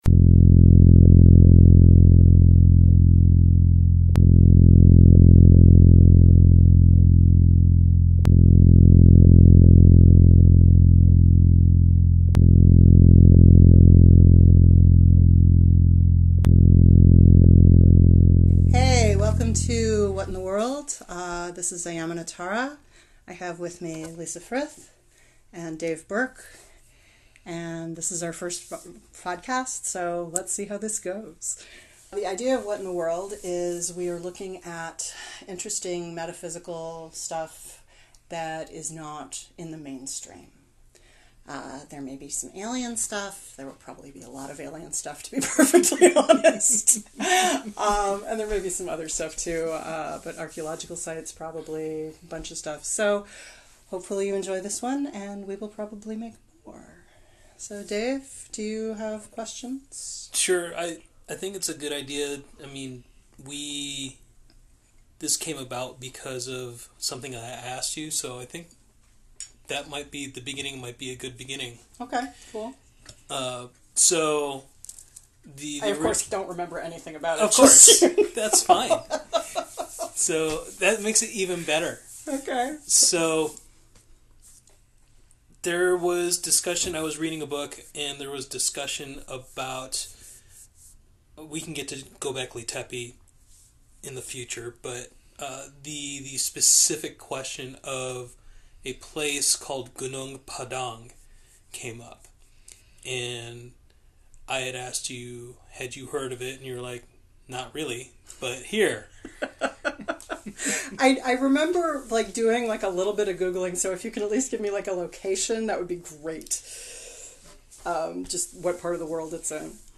Intro and Outro are a recording of a Keppler star, courtesy of NASA